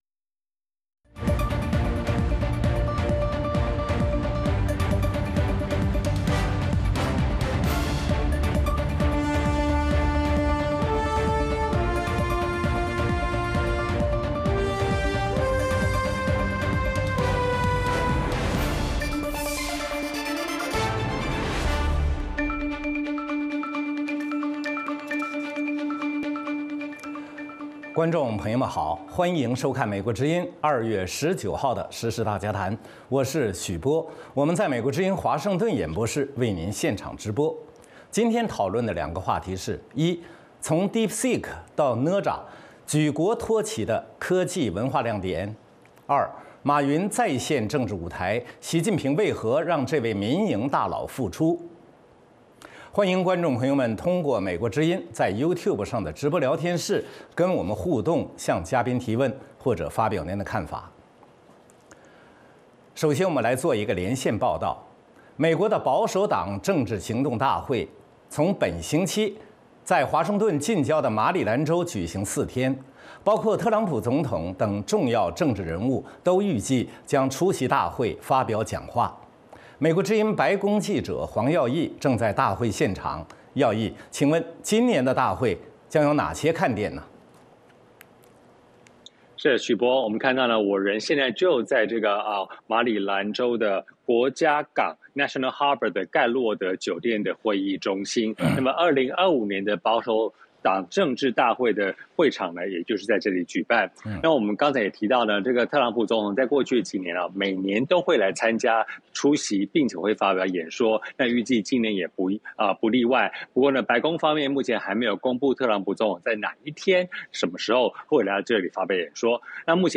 美国之音中文广播于北京时间晚上9点播出《时事大家谈》节目(电视、广播同步播出)。《时事大家谈》围绕重大事件、热点问题、区域冲突以及中国内政外交的重要方面，邀请专家和听众、观众进行现场对话和讨论，利用这个平台自由交换看法，探索事实。